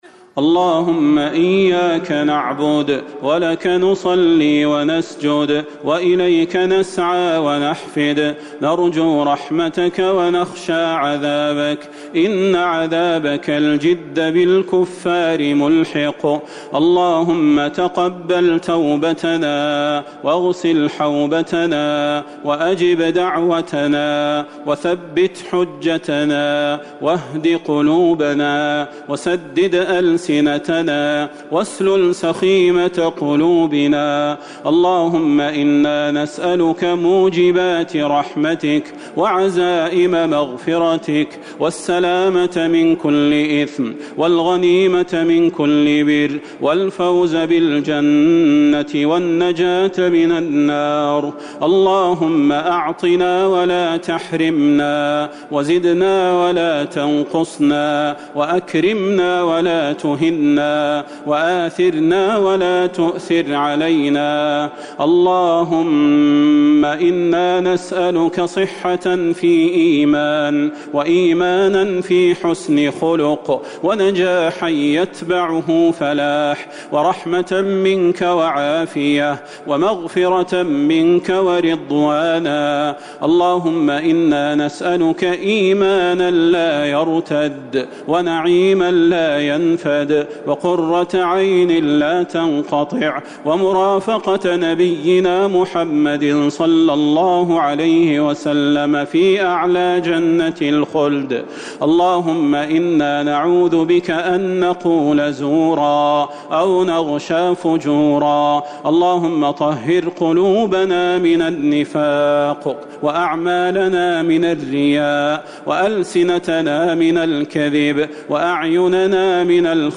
دعاء القنوت ليلة 10 رمضان 1441هـ > تراويح الحرم النبوي عام 1441 🕌 > التراويح - تلاوات الحرمين